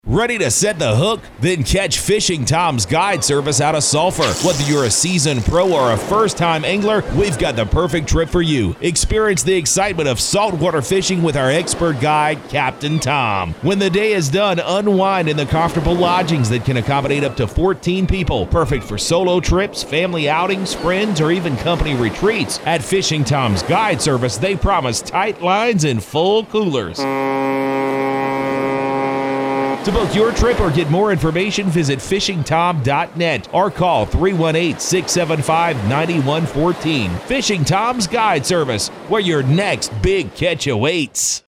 Radio Ad